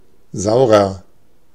Ääntäminen
Ääntäminen Tuntematon aksentti: IPA: /ˈzaʊ̯.ʁɐ/ Haettu sana löytyi näillä lähdekielillä: saksa Käännöksiä ei löytynyt valitulle kohdekielelle. Saurer on sanan sauer komparatiivi.